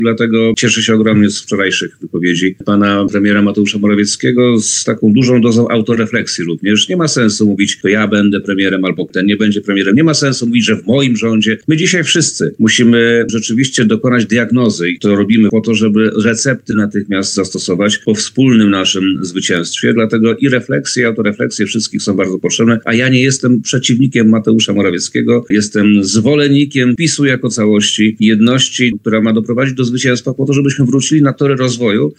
Wiceprezes Prawa i Sprawiedliwości, Przemysław Czarnek, skomentował na antenie Radia Lublin wyniki najnowszych sondaży pracowni Opinia24, w których Koalicja Obywatelska uzyskała niemal 33 procent głosów, a Prawo i Sprawiedliwość ponad 24,5 procent.